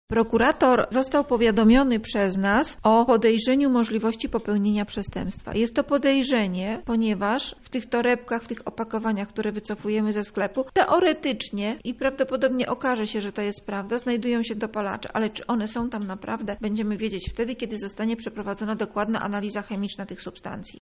O inspekcjach prowadzonych od pierwszych dni września mówi Irmina Nikiel Powiatowy Inspektor Sanitarny w Lublinie.